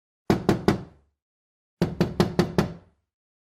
Звуки стука в окно
В коллекции представлены реалистичные эффекты: от легкого постукивания ногтями по стеклу до резких ударов кулаком.